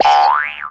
boing.wav